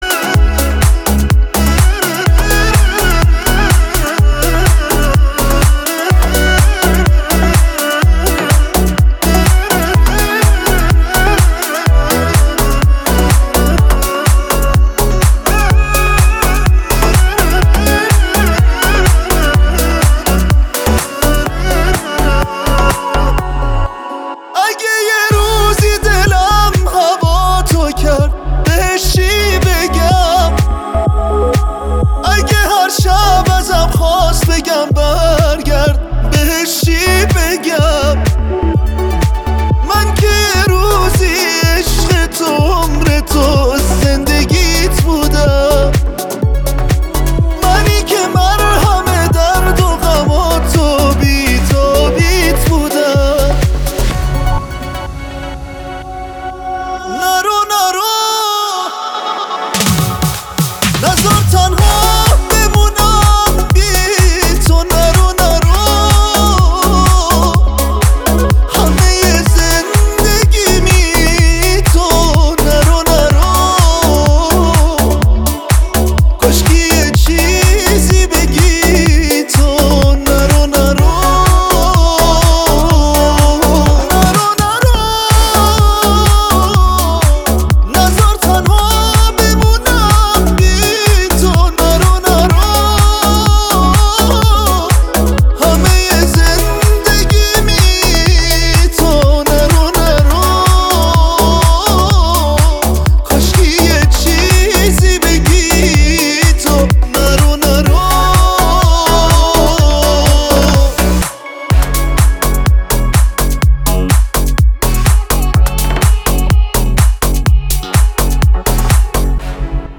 دانلود اهنگ پاپ